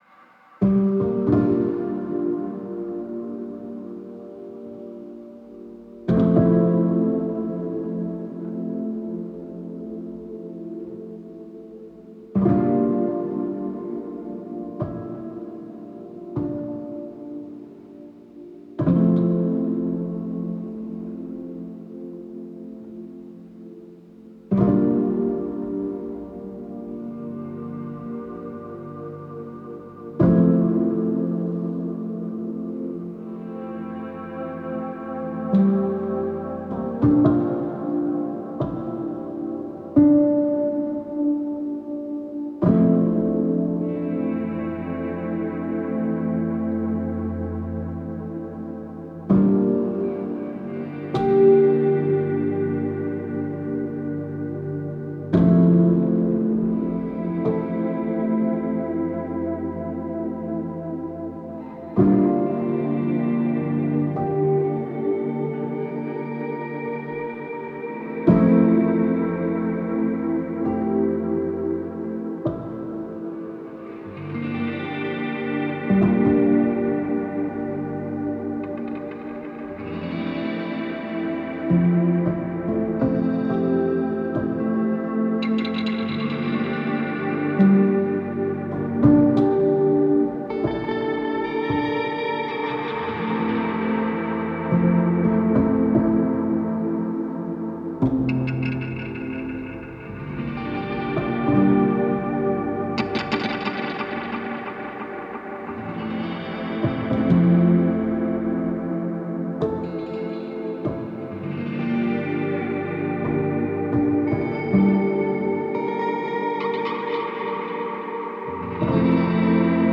C’est une accalmie paisible et temporaire